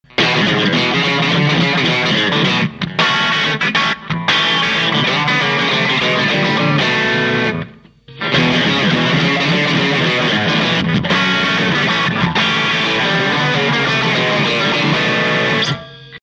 0.082はファット過ぎますし0.056だと変化が地味すぎるので0.068μＦに、とりあえずは